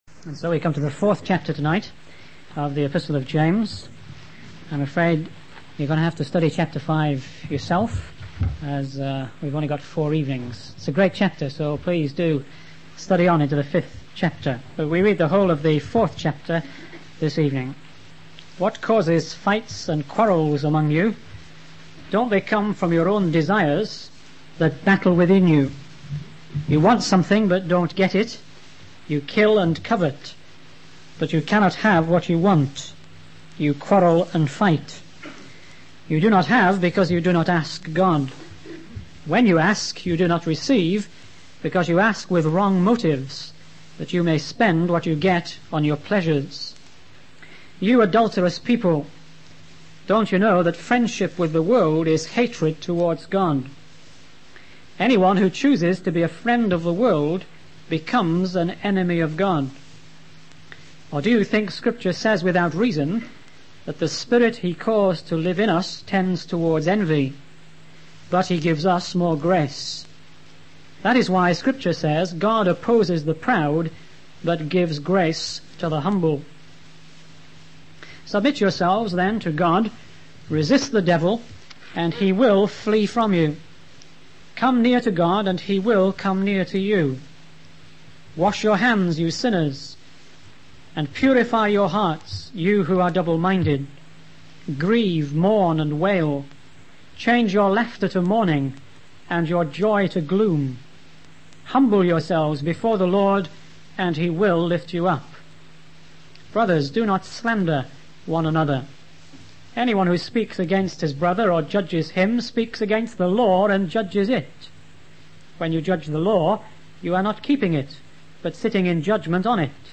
In this sermon, the preacher focuses on the fourth chapter of the Epistle of James. He emphasizes the importance of understanding the transient nature of life and the need to submit to the Lord's will.